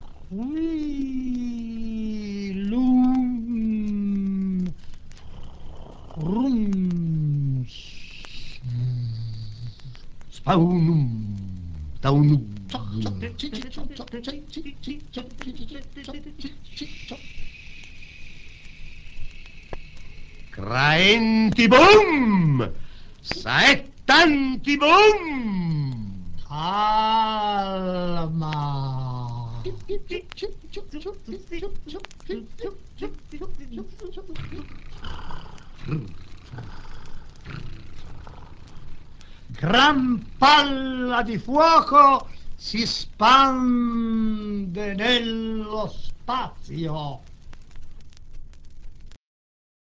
Poesia parolibera di Balla, eseguita dal Trio Exvoco Paesaggio + Temporale